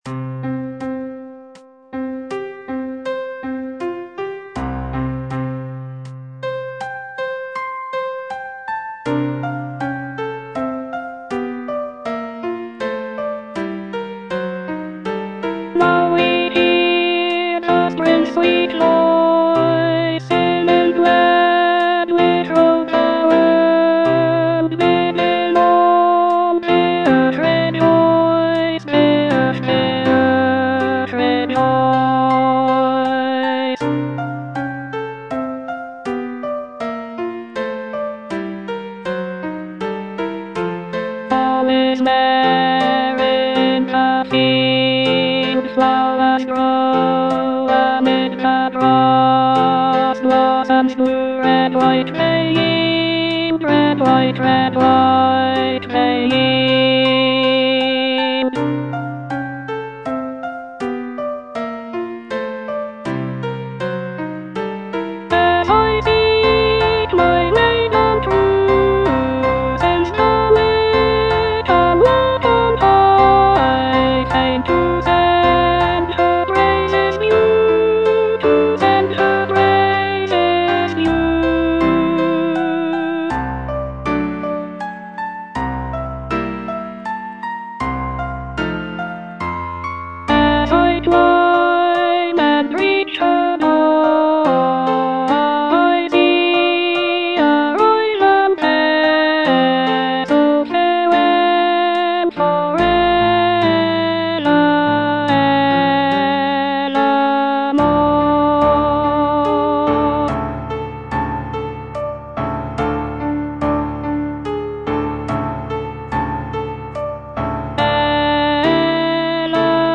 E. ELGAR - FROM THE BAVARIAN HIGHLANDS False love - Alto (Voice with metronome) Ads stop: auto-stop